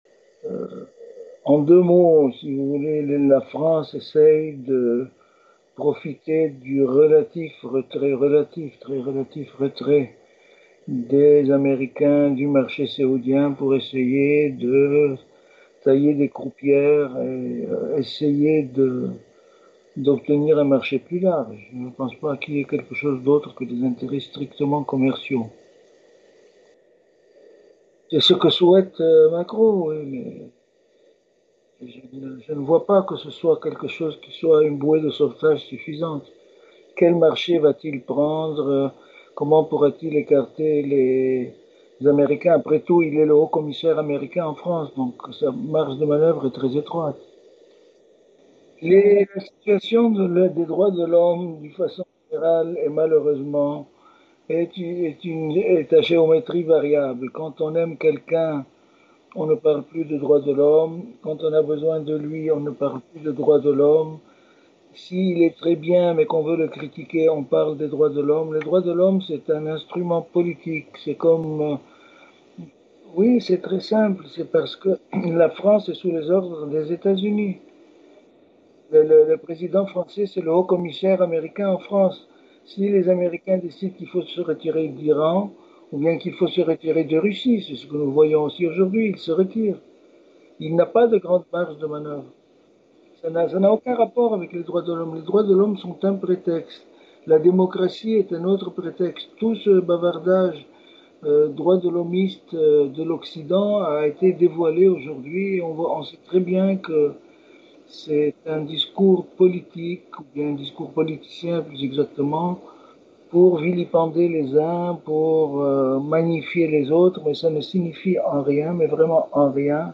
Mots clés France Arabie saoudie interview Eléments connexes Reconnaissance de la Palestine par Macron : réalisme ou geste symbol